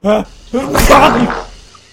PLAY Kichnięcie z niespodzianką extra
kichniecie-z-niespodzianka.mp3